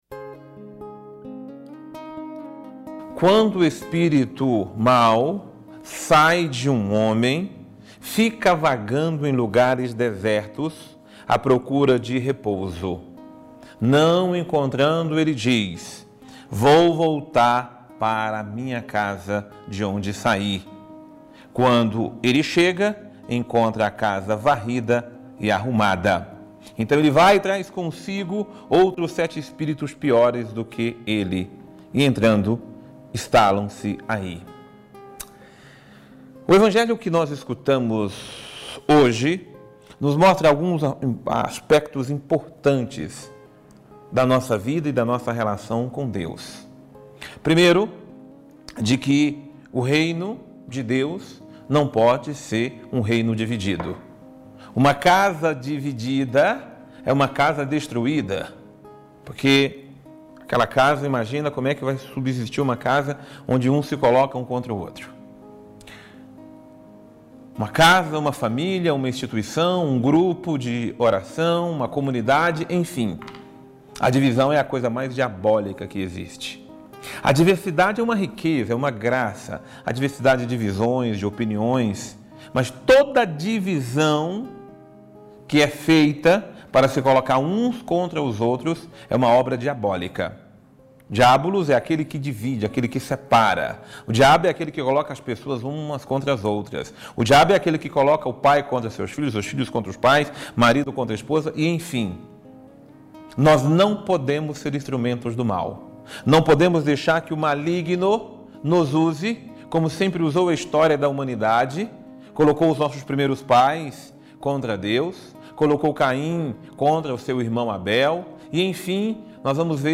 Homilia diária - O maligno semeia a divisão em nossa vida